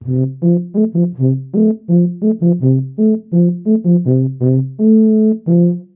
标签： waltz brass tuba guitar christmas holidays
声道立体声